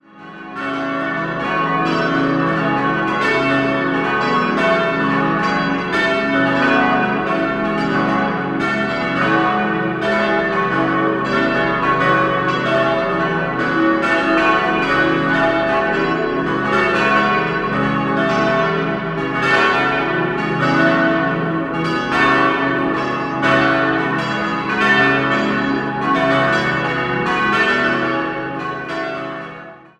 Die Pfarrkirche wurde 1905 bis 1908 nach den Plänen von Josef Schmitz errichtet. 5-stimmiges Geläut: cis'-e'-fis'-gis'-h' Die Glocken wurden 1954 bei der Gießerei Grassmayr in Innsbruck gefertigt.